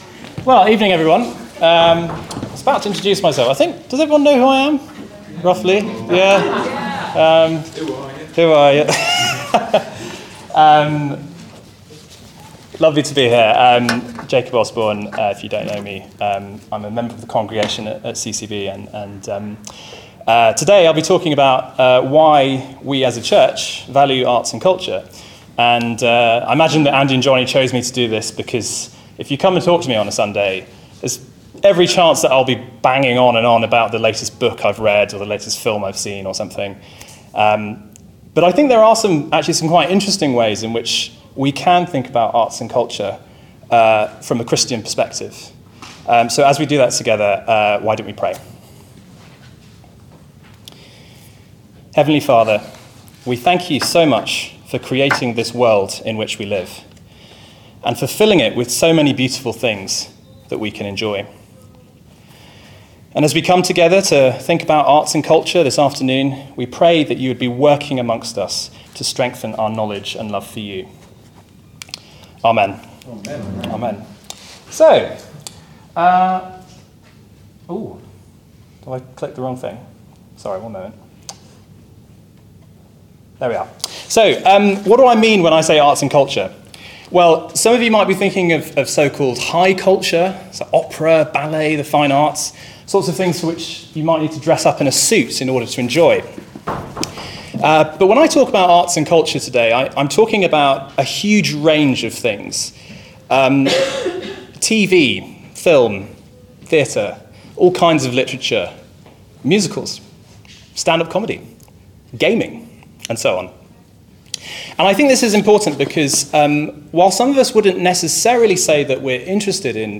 Weekly talks from Christ Church Balham's Sunday service